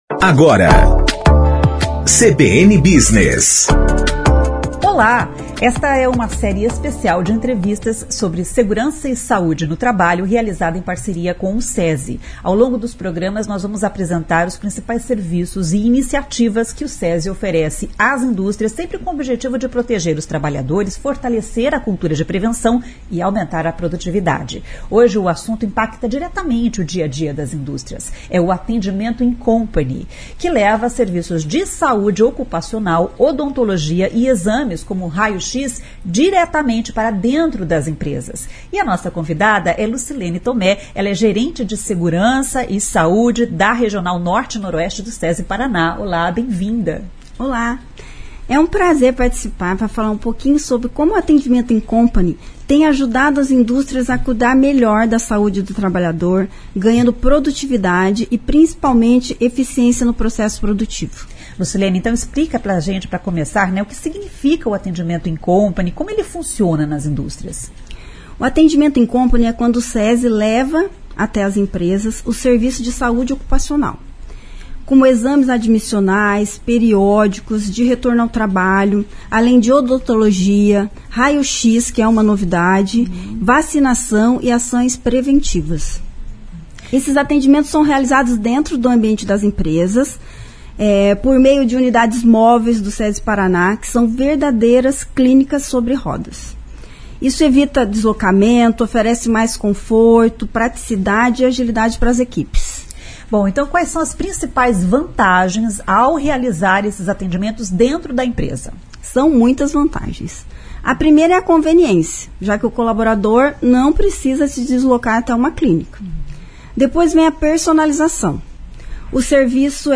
Esta é uma série especial de entrevistas sobre Segurança e Saúde no Trabalho, realizada em parceria com o Sesi.